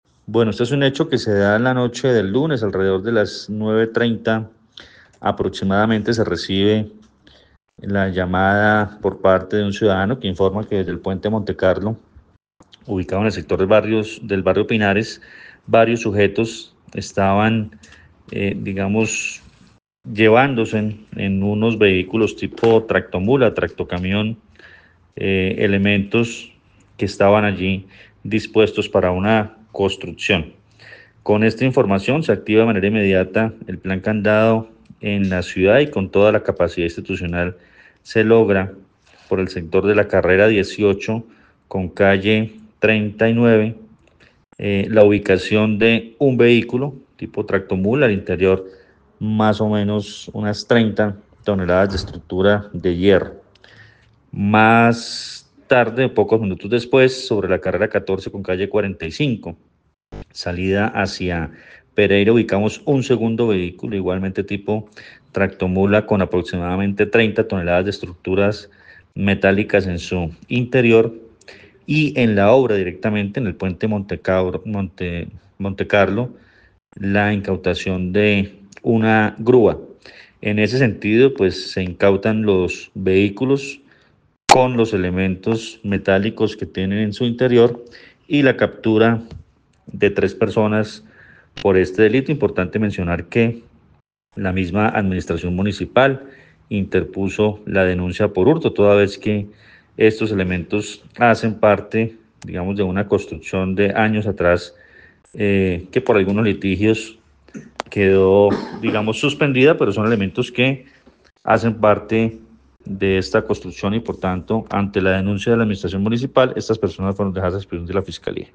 Coronel Luis Fernando Atuesta, comandante de la Policía del Quindío